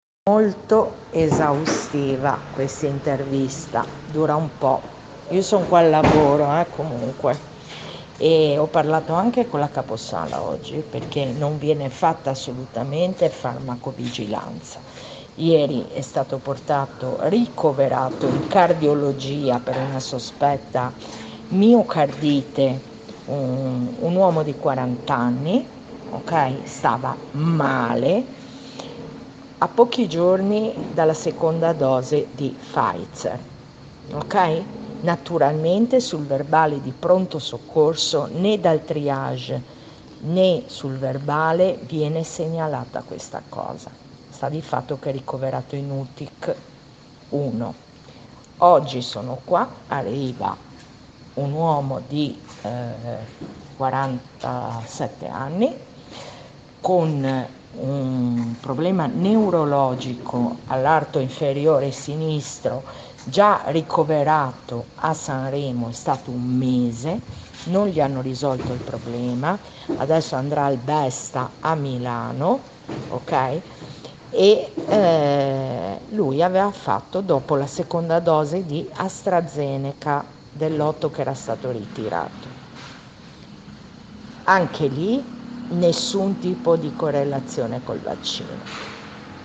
Testimonianza choc di una dottoressa ligure sulle mancate segnalazioni relative alle conseguenze letali dei cosiddetti "vaccini".